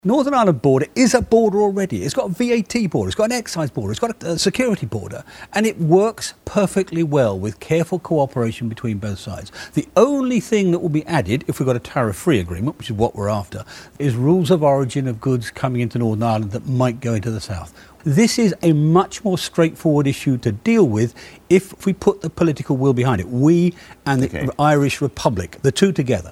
Mr Davis told the BBC’s Andrew Marr Show – that agreement can be reached with political will: